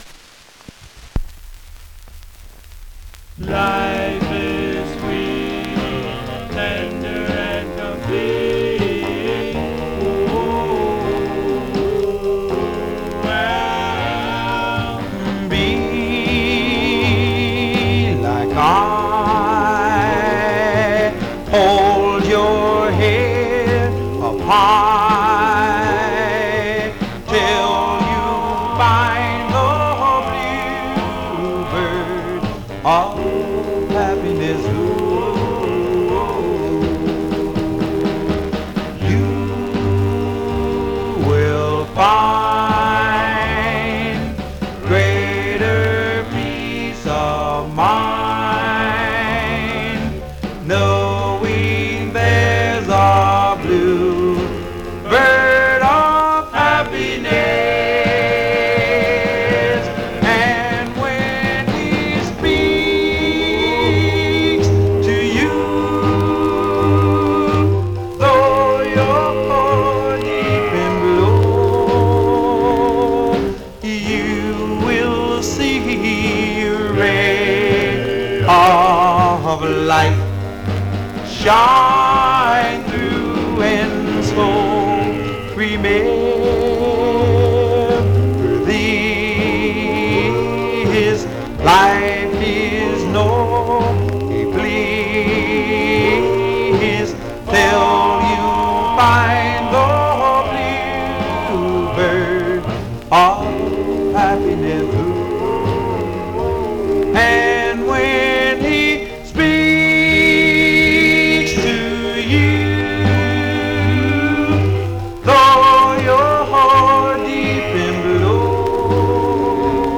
Condition Some surface noise/wear Stereo/mono Mono
Male Black Group